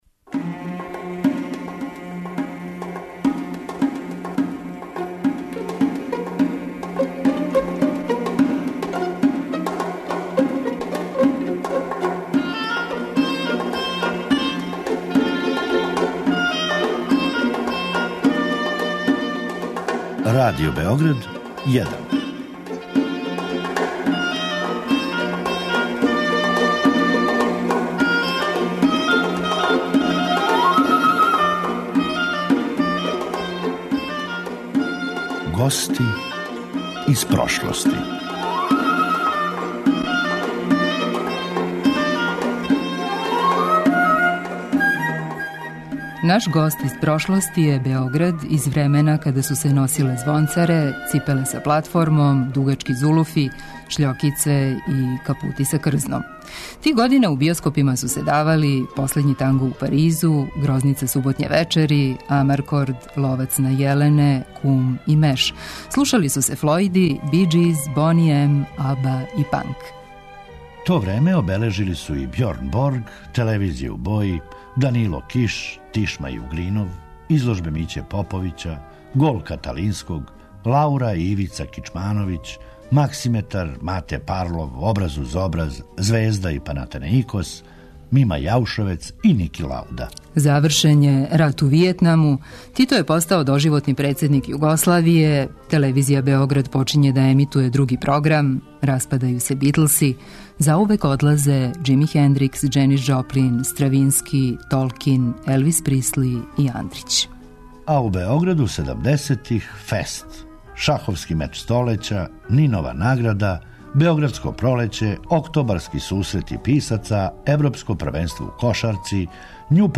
Четири године касније дошао је поново, а био је и гост емисије 'Радиоскоп' Радио Београда, о чему је остао запис у нашем Тонском архиву.